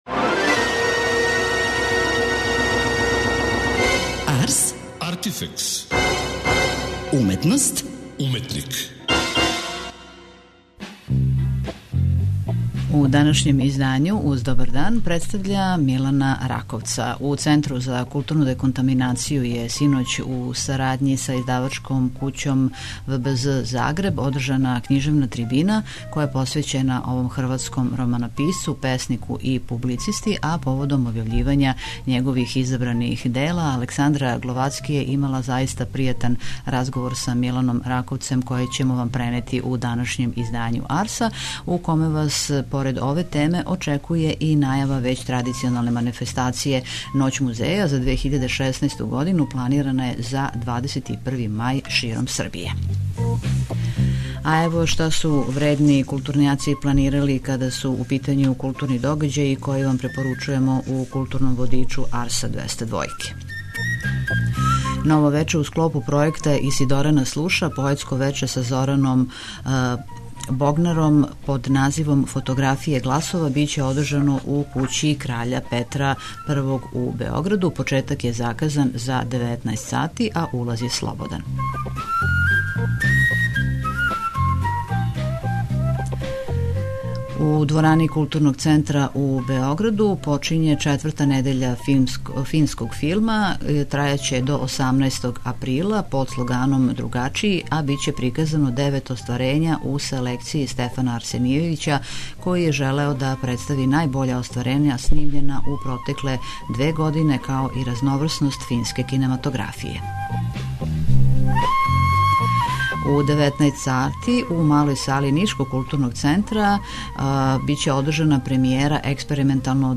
преузми : 27.91 MB Ars, Artifex Autor: Београд 202 Ars, artifex најављује, прати, коментарише ars/уметност и artifex/уметника. Брзо, кратко, критички - да будете у току.